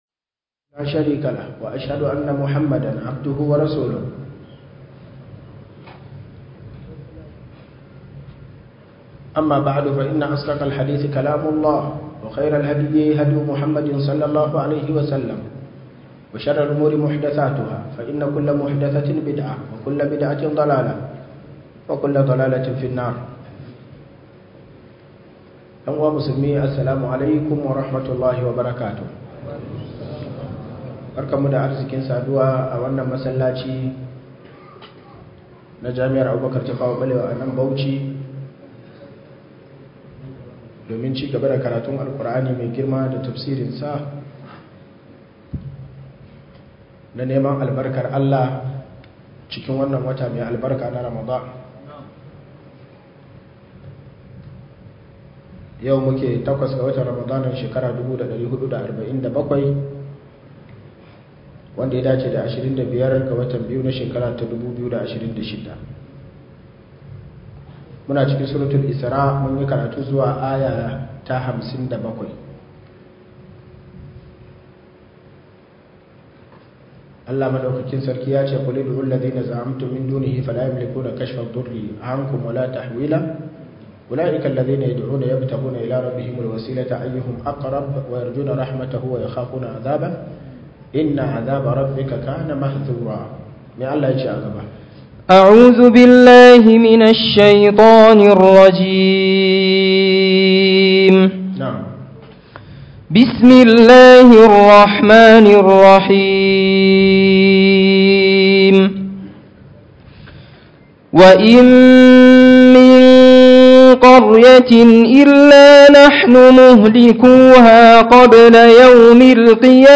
← Back to Audio Lectures 08 Ramadan Tafsir Copied!